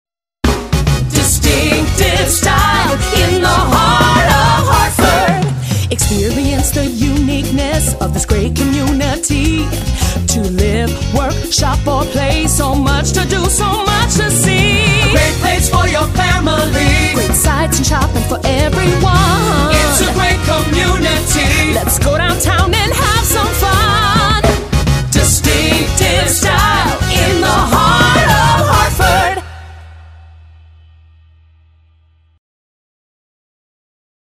Tags: Jingle Music Marketing Musical Image Branding